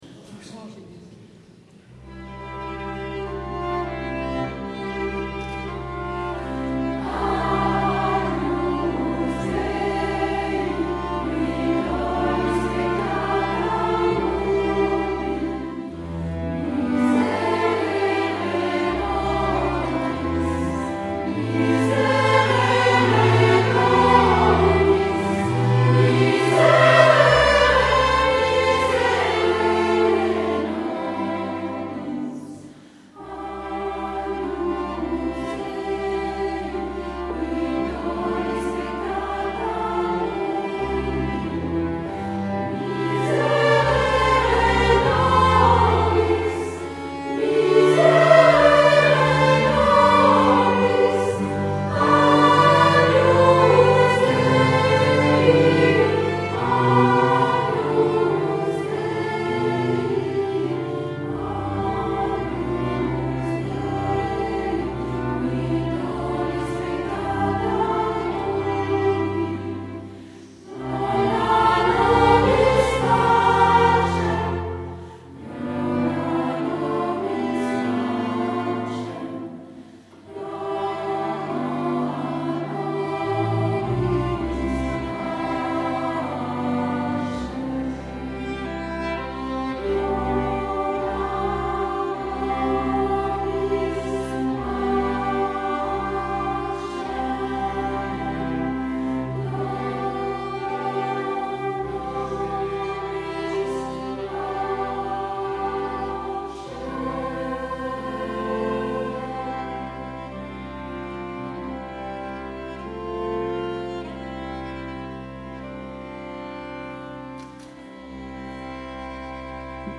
Ensemble vocal féminin